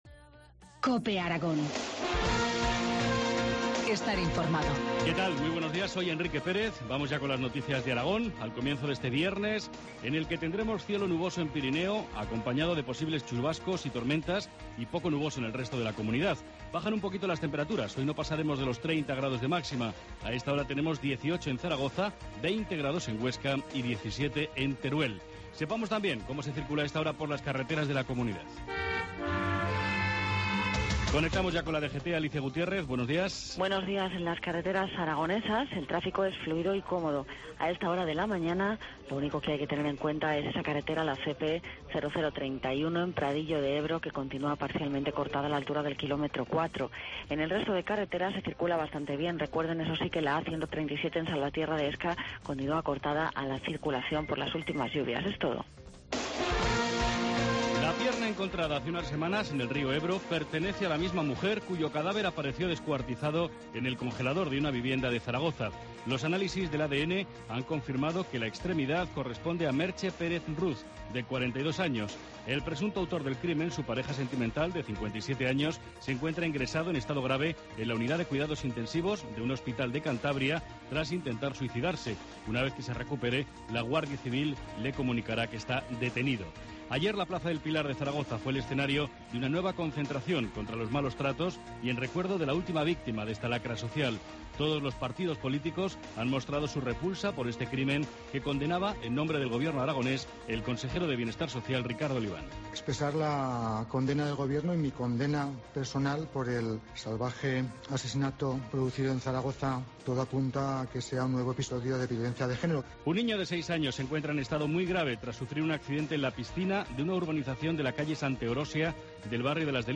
Informativo matinal, viernes 14 de junio, 7.25 horas